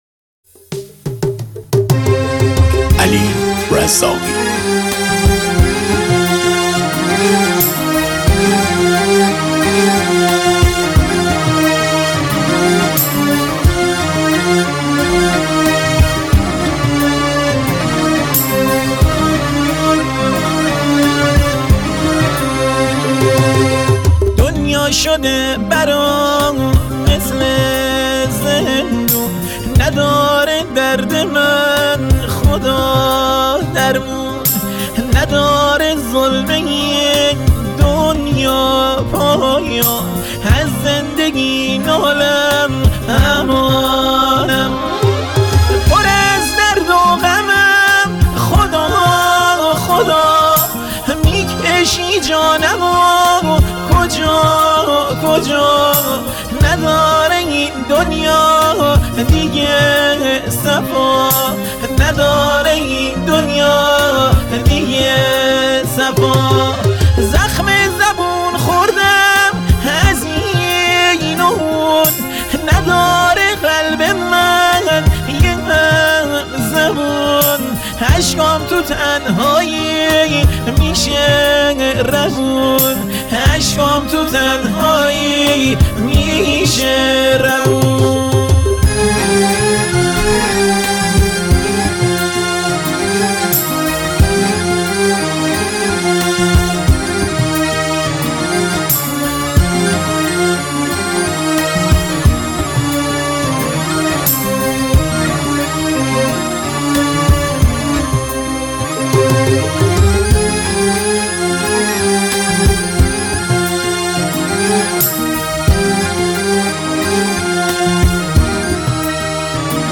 متن آهنگ محلی